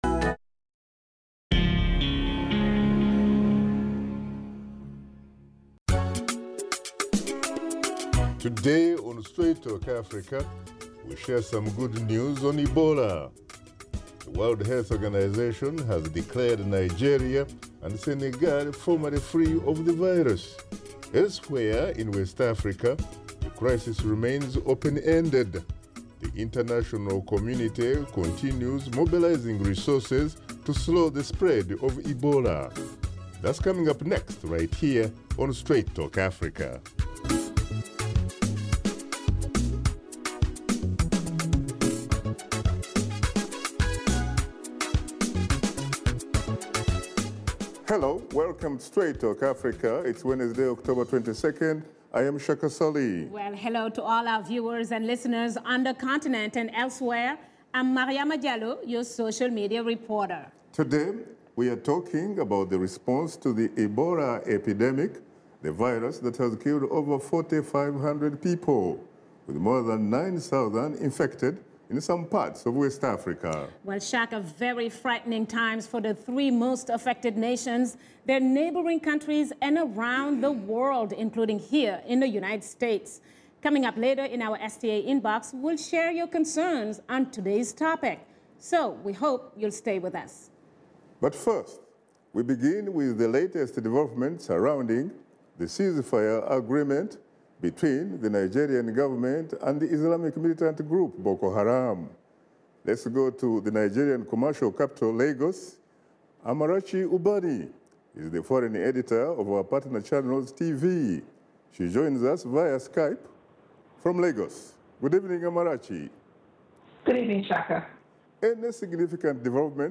Washington Studio Guests